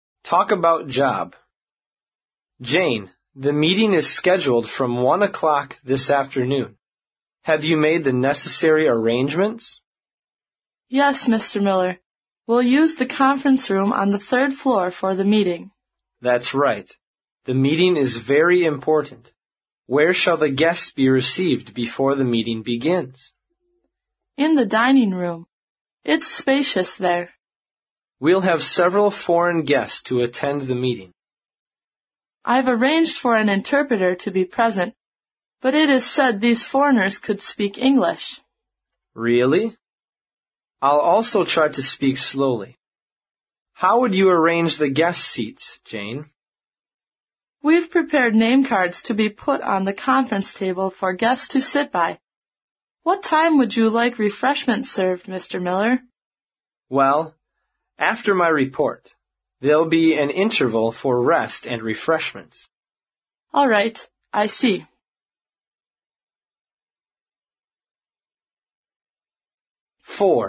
在线英语听力室外贸英语话题王 第3期:谈论工作的听力文件下载,《外贸英语话题王》通过经典的英语口语对话内容，学习外贸英语知识，积累外贸英语词汇，潜移默化中培养英语语感。